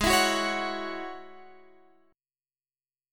Listen to G#7sus4#5 strummed